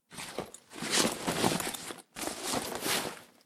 liz_backpack_unequip.ogg